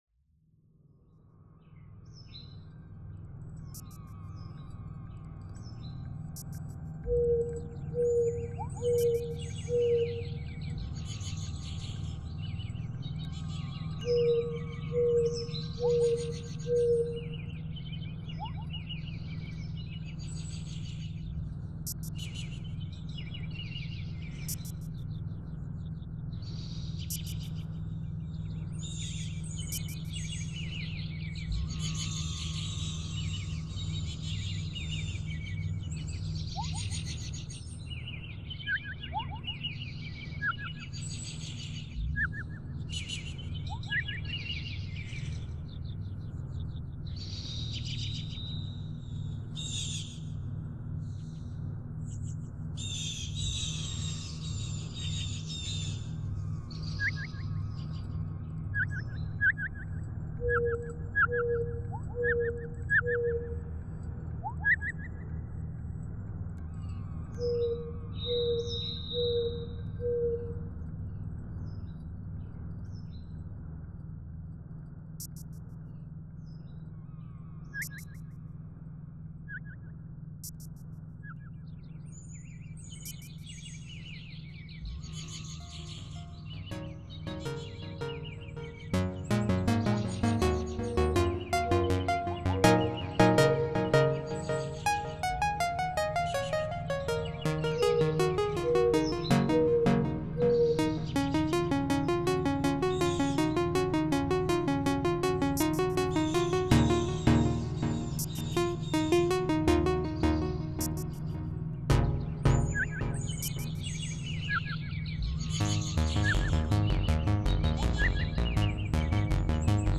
Ambient tracks made with synthesizers and bird cries